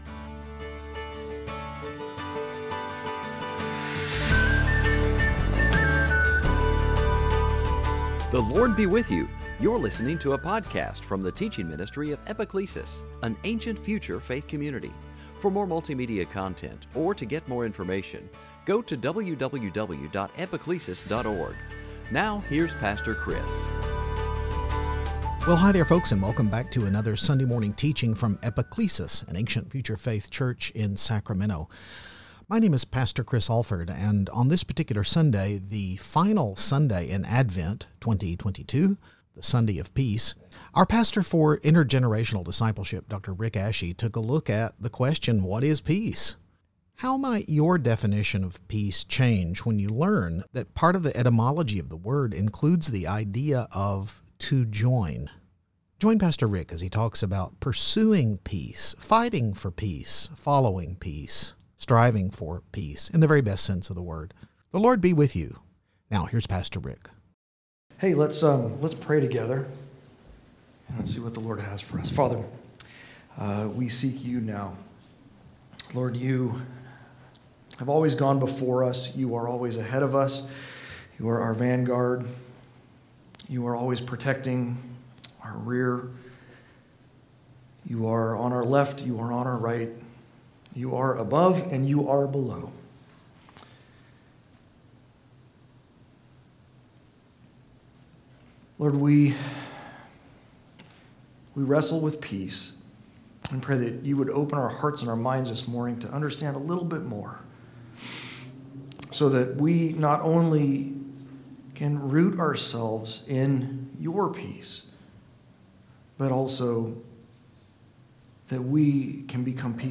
2022 Sunday Teaching Christmas death Joseph joy Mary peace The Book of God Walter Wangerin Advent